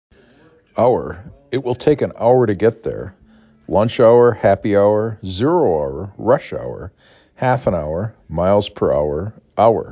4 Letters, 1 or 2 Syllable
2 Phonemes
ow r
ow er